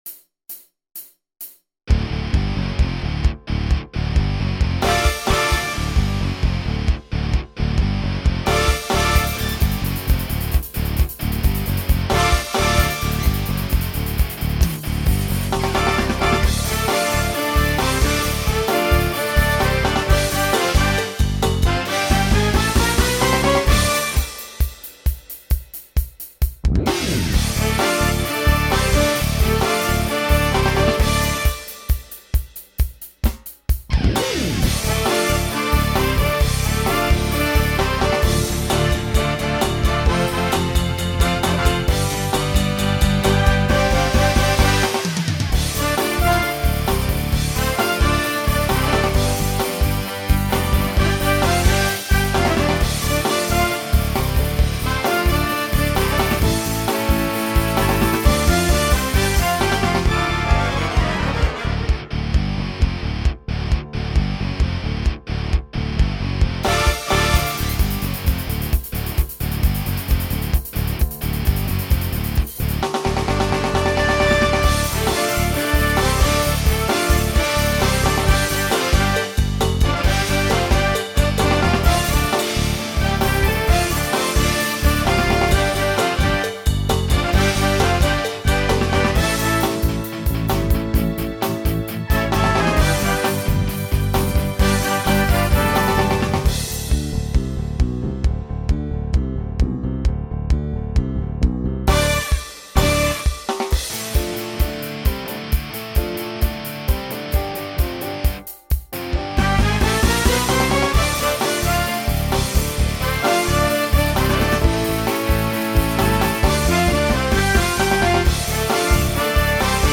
Voicing SATB Instrumental combo Genre Country